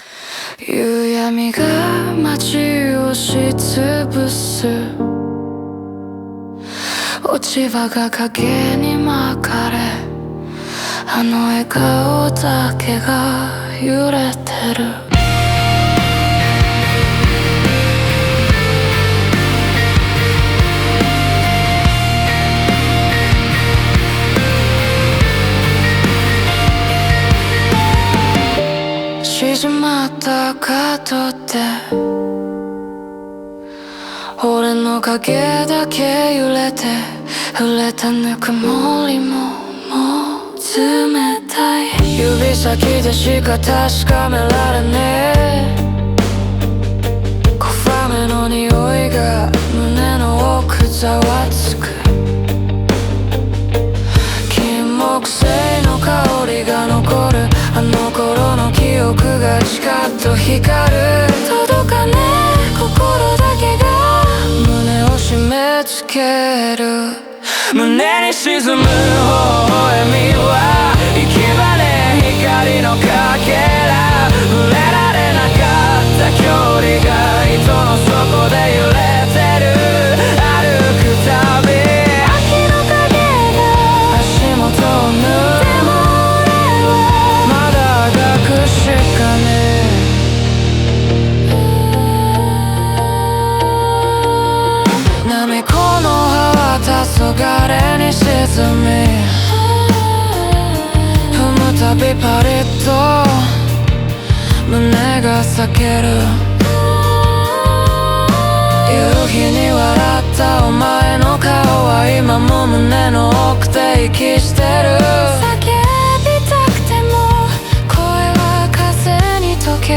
若い男性の不良っぽい口語表現と荒れた投げやりな語りが、切なさや恋しさ、悲劇的感情を強く伝えています。